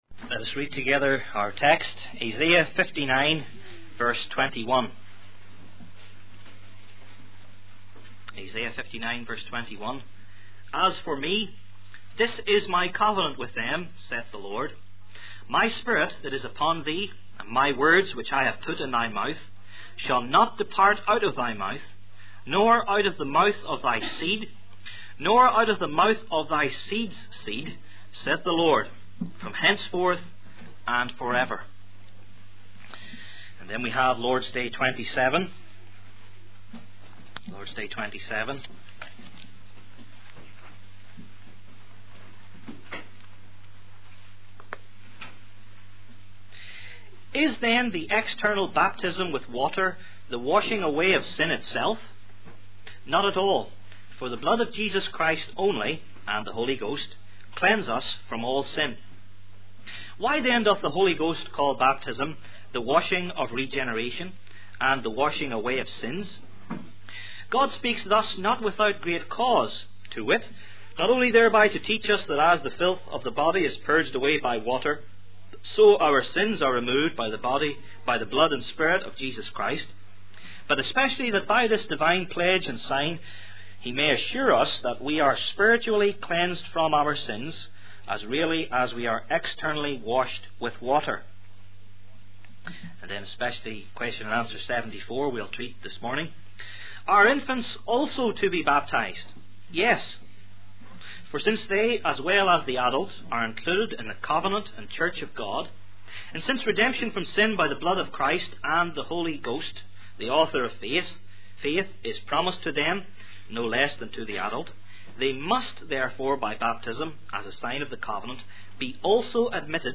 Heidelberg Catechism Sermons